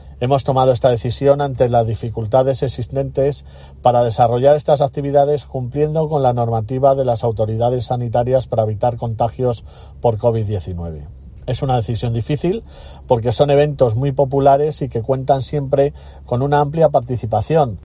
Carlos López, concejal de Turismo